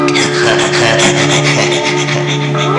Crazy Laughter Sound Effect
Download a high-quality crazy laughter sound effect.
crazy-laughter.mp3